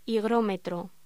Locución: Higrómetro
voz